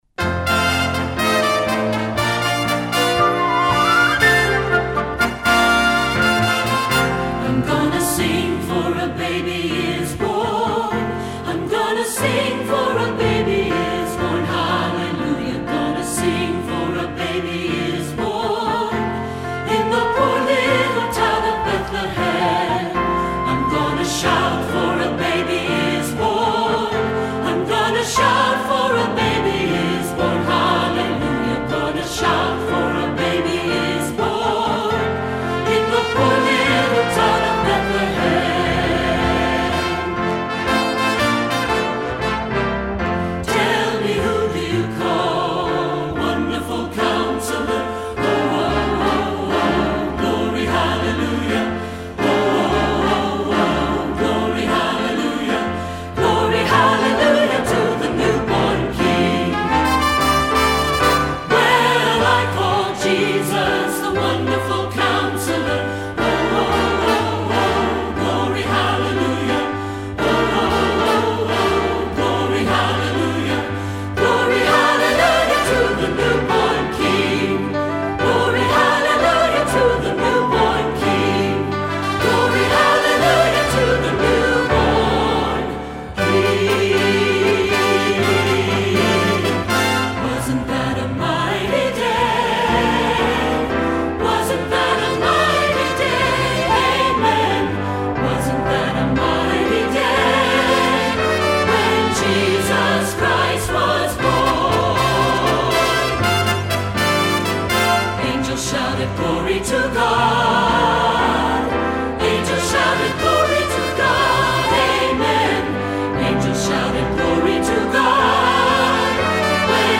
lively spiritual medley
The parts for flute and brass are outstanding!
SAB